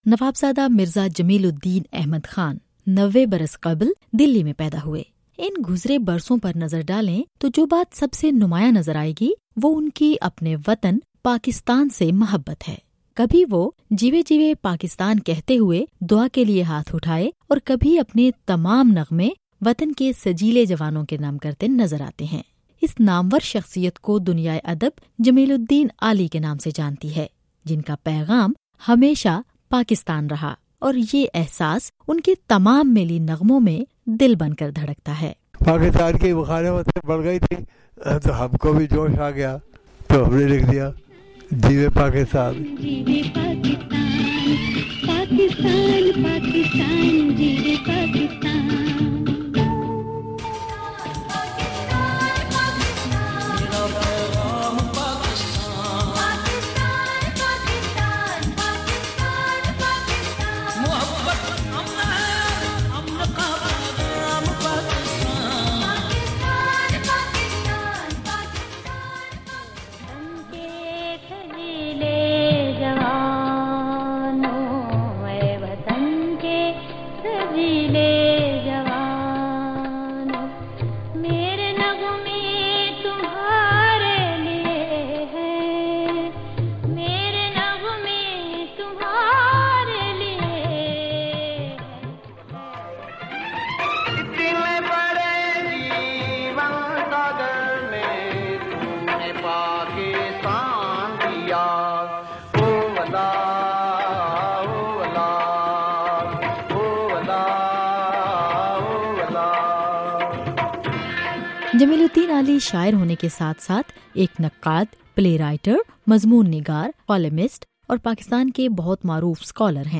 انٹرویو - جمیل الدین عالی